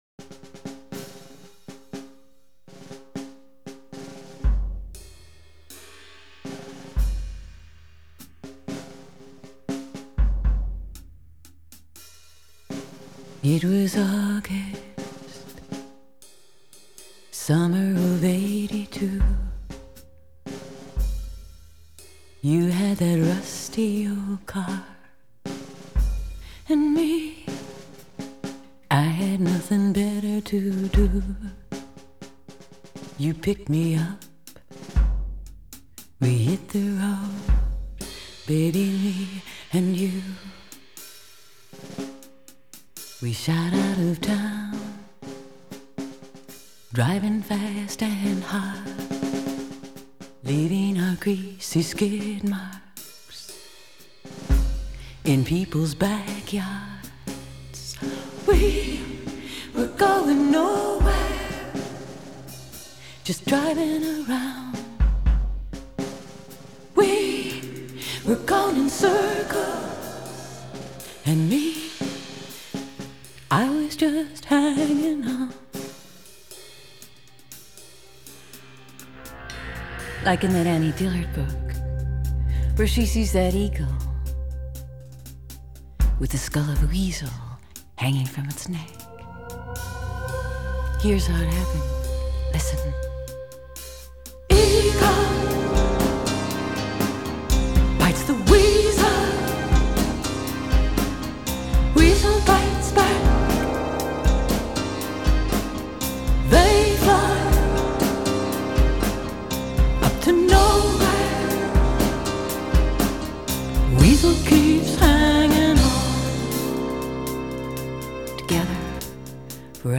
Art pop, electronic, avant-garde, spoken word, Experimental